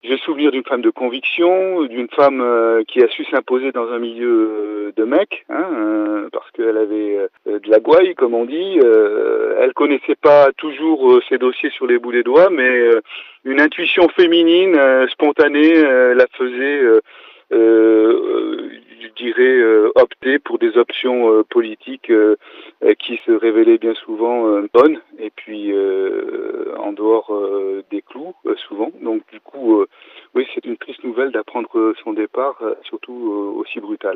Joint par Chérie FM Vallée du Rhône, l’ancien maire PS évoque une « triste nouvelle ».